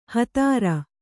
♪ hatāra